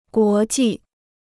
国际 (guó jì): international.